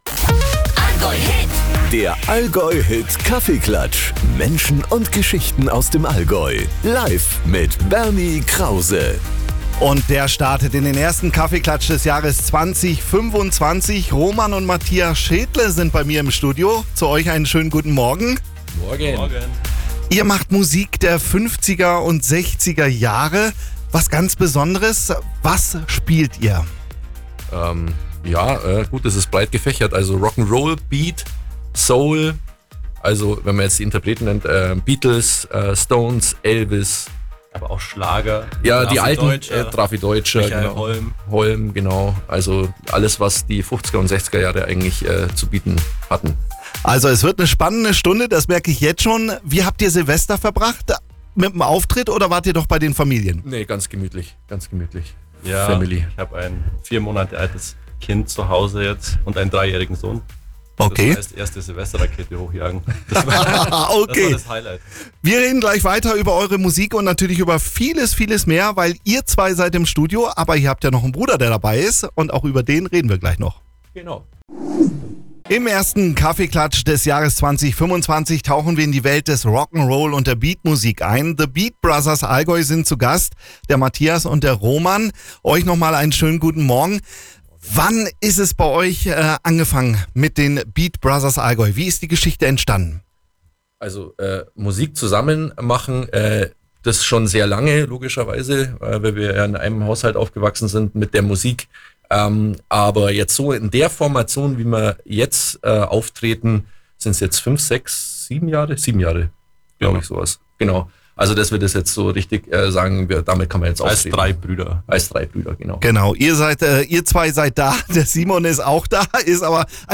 Im ersten Kaffeeklatsch des Jahres 2025 waren die Beat Brothers Allgäu bei uns zu Gast im Studio.